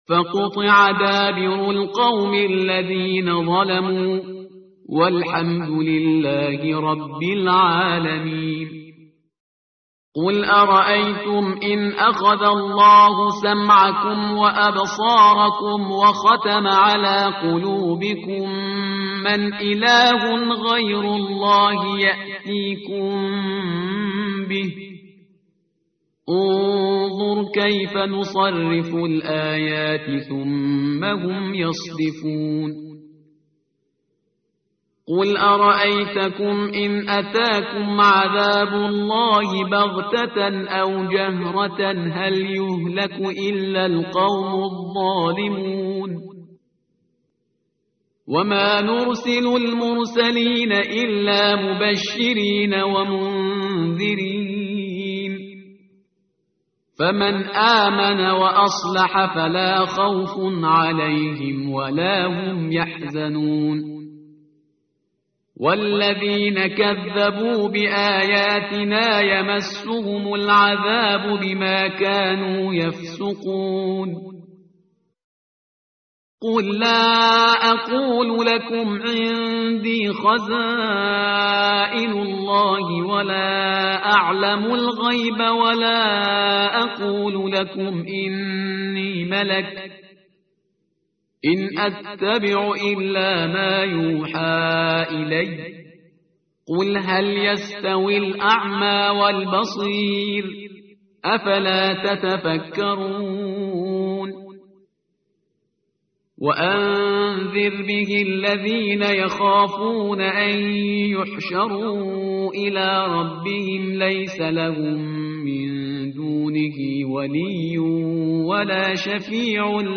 متن قرآن همراه باتلاوت قرآن و ترجمه
tartil_parhizgar_page_133.mp3